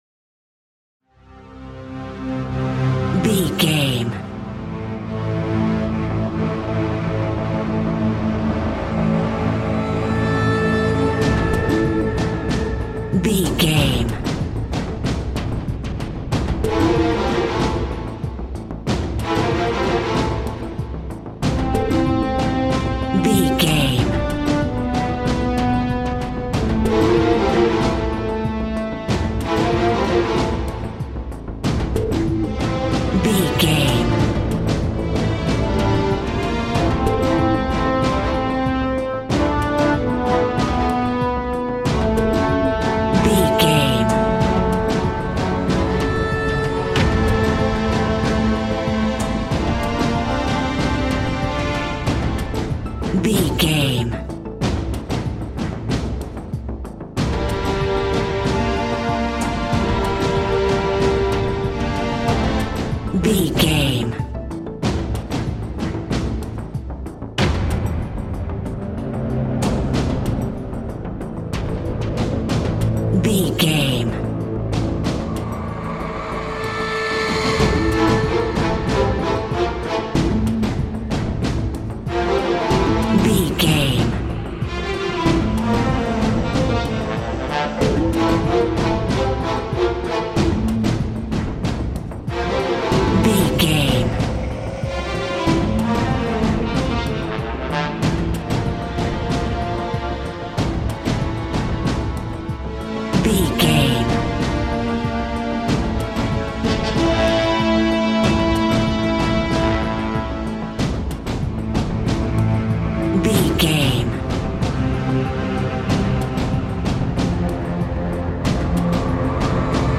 Metroidvania
Aeolian/Minor
angry
futuristic
aggressive
orchestra
percussion
synthesiser
dark
mechanical